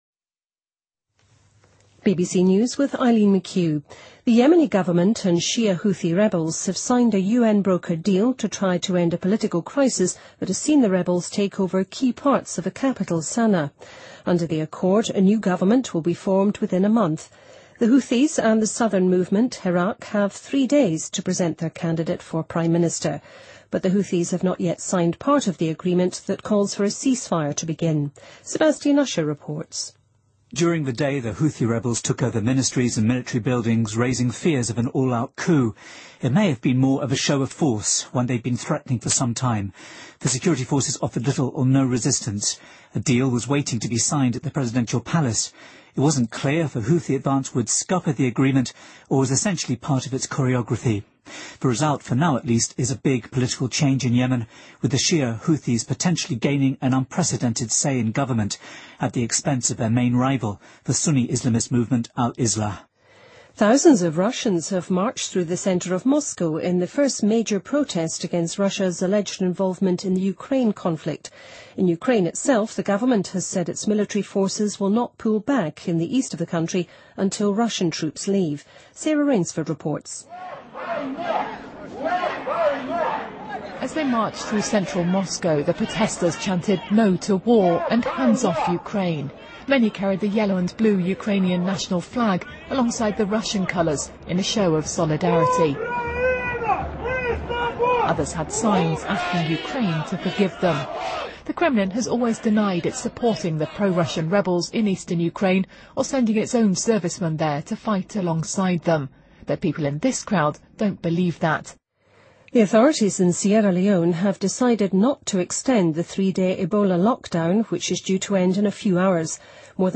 BBC news,俄罗斯人抗议反对俄参与乌克兰冲突